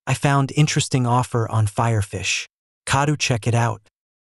- Add notification sound file
notification.wav